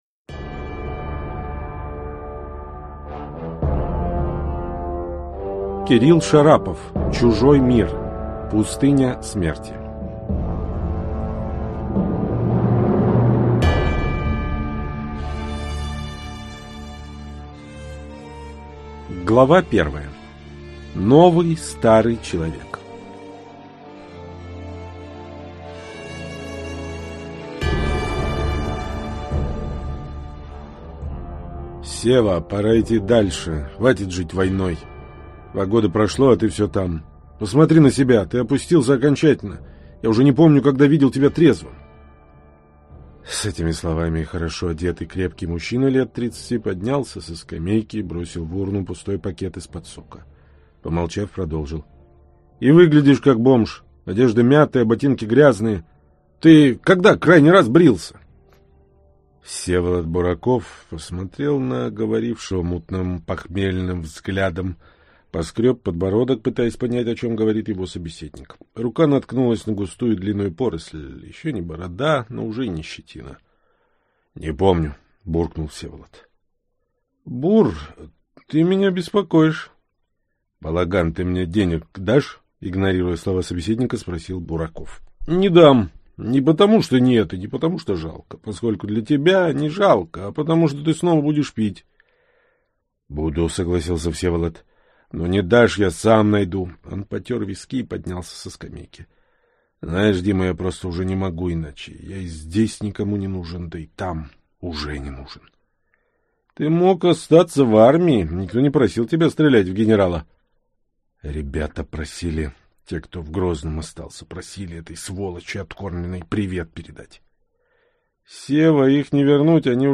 Аудиокнига Чужой мир. Пустыня смерти | Библиотека аудиокниг